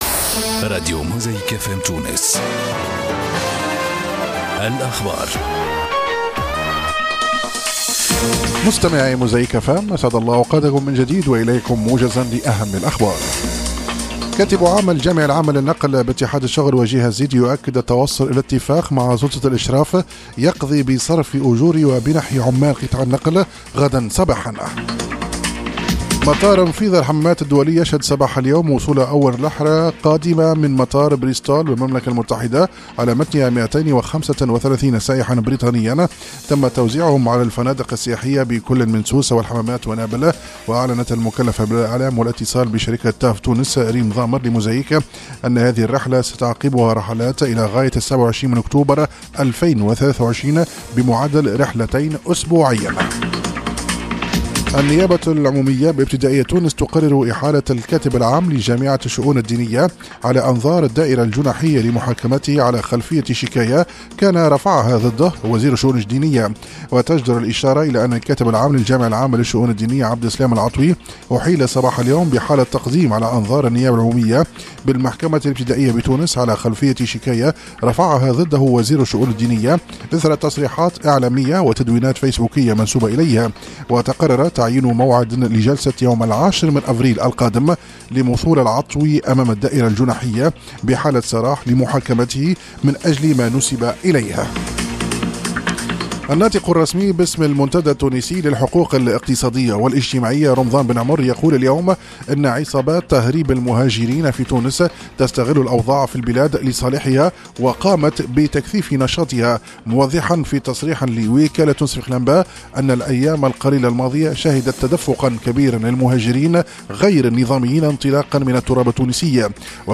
الاخبار 27/03/2023 16:00